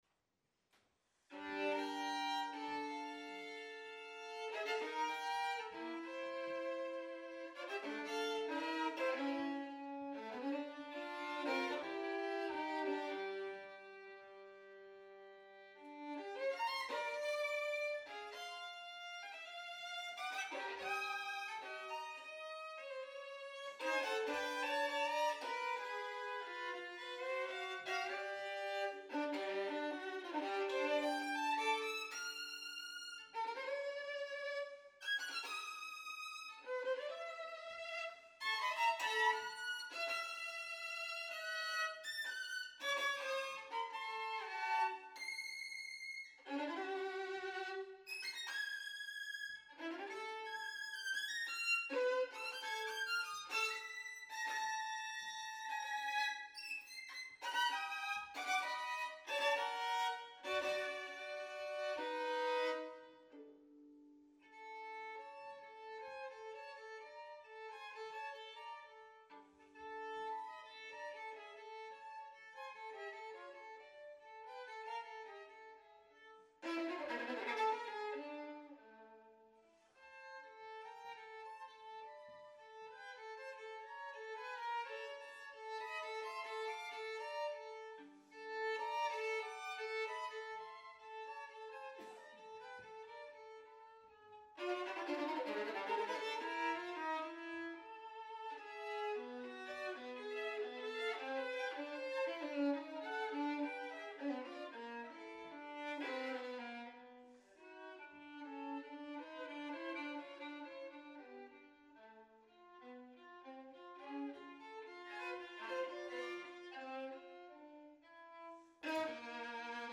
Venue: Bantry Library
vn Instrumentation Category:Solo Artists